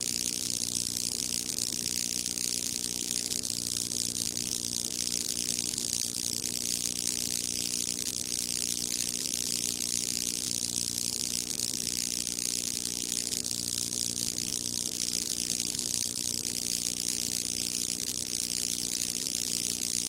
Tiếng Ghế điện kêu, tiếng Ngồi ghế điện…
Thể loại: Âm thanh hung dữ ghê sợ
Description: Tiếng ghế điện vang lên rít rít, rè rè, chập chờn như dòng điện phóng qua kim loại. Âm thanh tra tấn ghê rợn, tiếng điện nổ lép bép, xèo xèo, tiếng rung bần bật của ghế kim loại. Cảm giác ngồi trên ghế điện lạnh buốt, dòng điện chạy qua người, phát ra tiếng rét rét, ù ù, tạo nên hiệu ứng âm thanh chân thực, ám ảnh, thích hợp cho cảnh tra tấn, kinh dị hoặc hiệu ứng điện giật trong video.
tieng-ghe-dien-keu-tieng-ngoi-ghe-dien-www_tiengdong_com.mp3